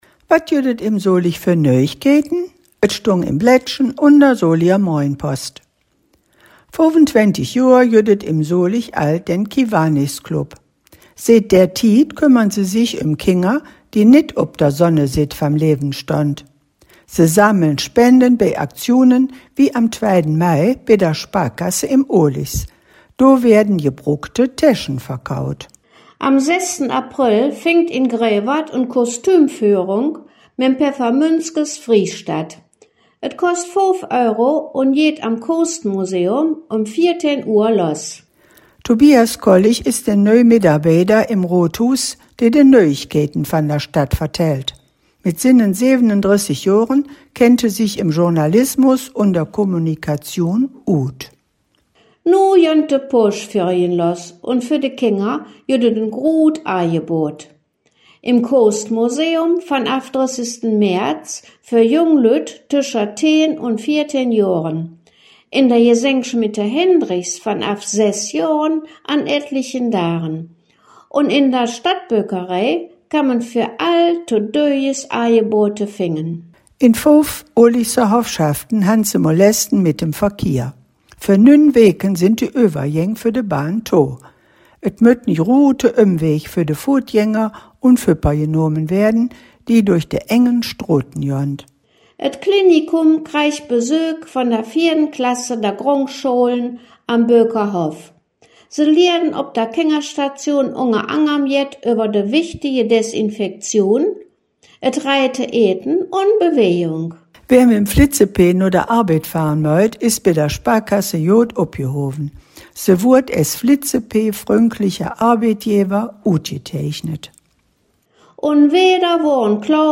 Der in Solingen gesprochene Dialekt wird Solinger Platt genannt.
Zugleich wird im Solinger Platt aber auch die Nähe zum ripuarischen Sprachraum (vor allem durch das Kölsch bekannt) hörbar.